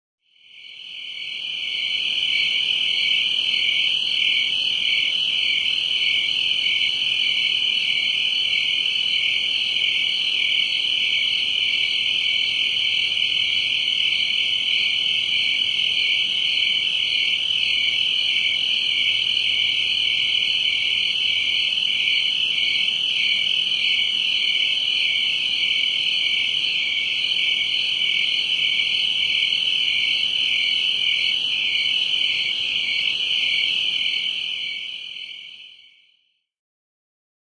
The Tree Cricket
The chirping sound is slightly higher than the highest octave on a piano.
crickets.mp3